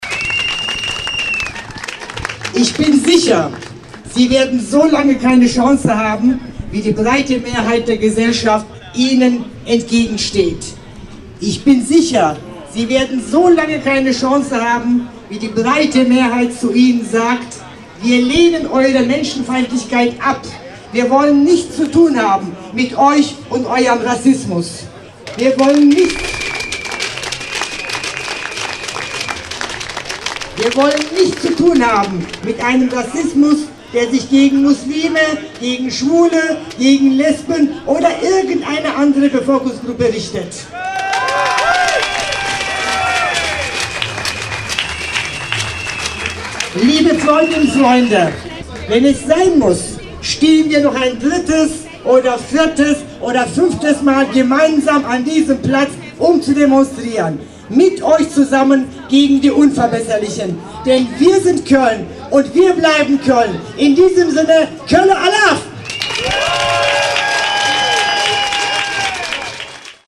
Nach einer kurzen Auftaktkundgebung zogen die Demonstranten über die Deutzer Brücke zum Kundgebungsort vor dem KölnTriangle („LVR-Turm“), wo die Kölner SPD-Bundestagsabgeordnete Lale Akgün über „Pro Köln“ und den Rassisten-Kongress um die Ecke sagte:
die Rede von Lale Akgün
im O-Ton